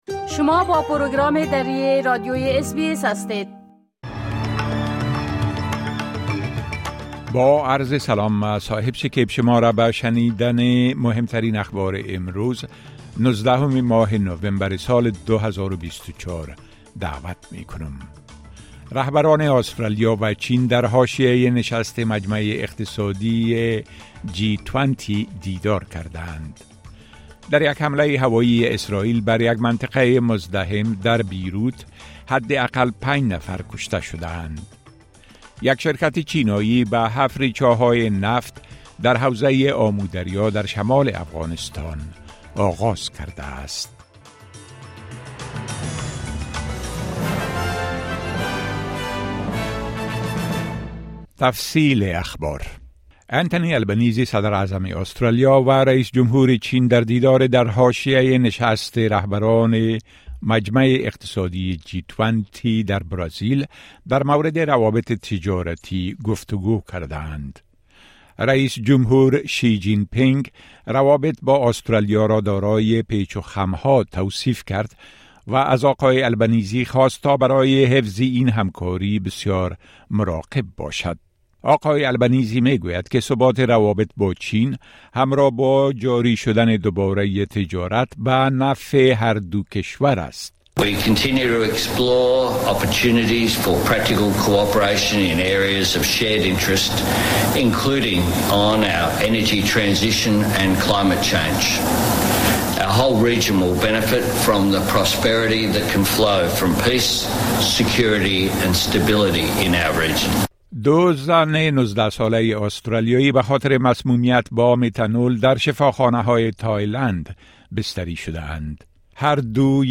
مهمترين اخبار روز از بخش درى راديوى اس بى اس